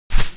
Sfx Player Wingflap Sound Effect
Download a high-quality sfx player wingflap sound effect.
sfx-player-wingflap-1.mp3